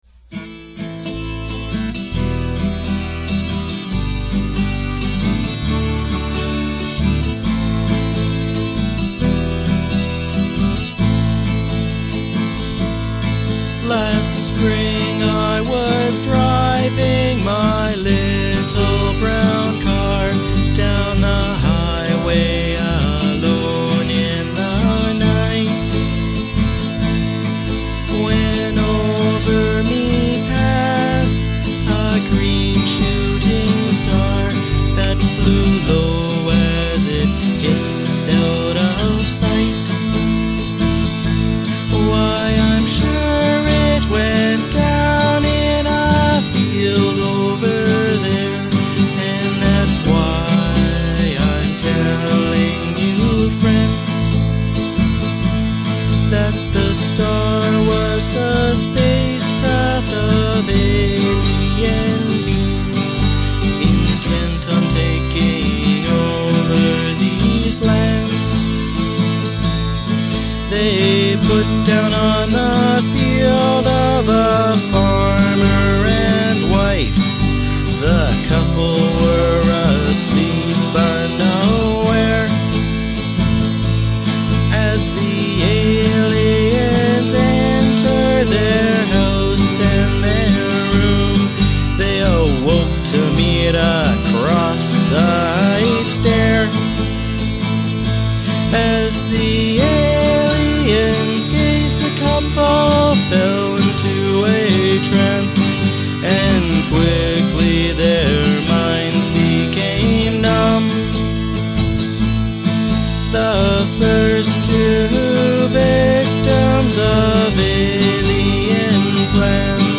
This song is a 7 minute epic – a story about mind-controlling aliens, featuring a great kazoo solo, and some fun harmonies.